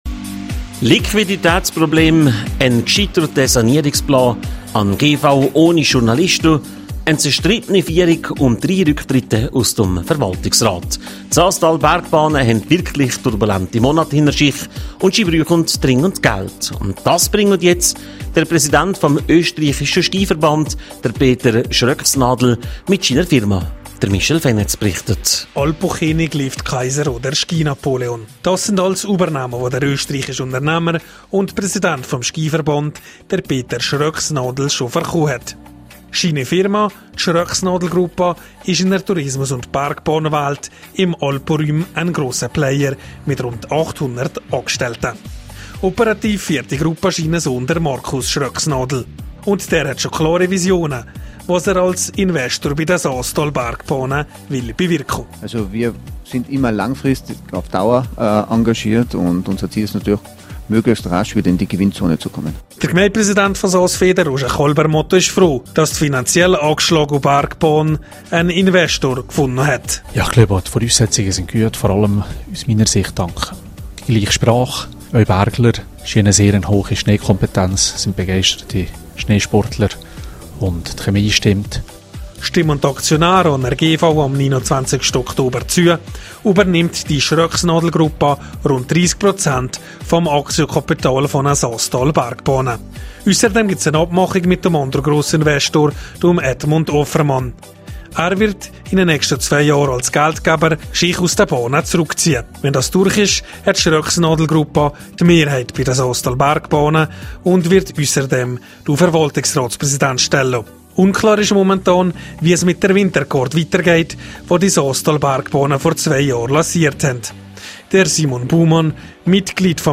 24097_News.mp3